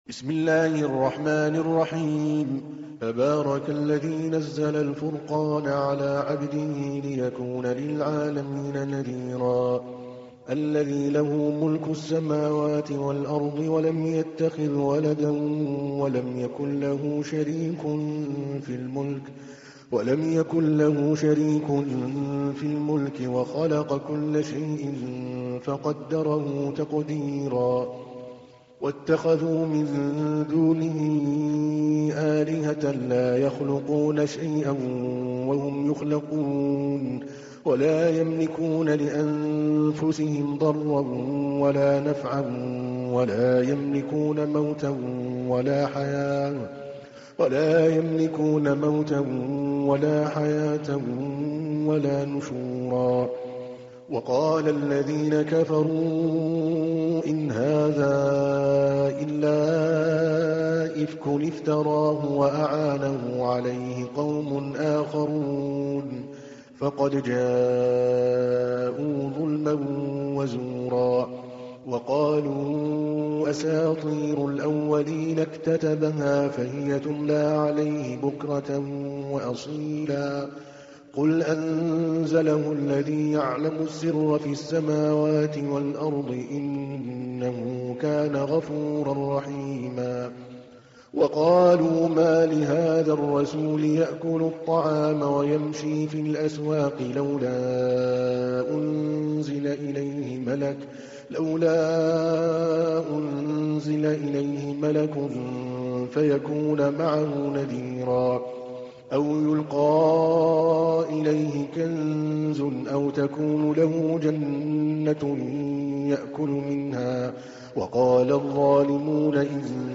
تحميل : 25. سورة الفرقان / القارئ عادل الكلباني / القرآن الكريم / موقع يا حسين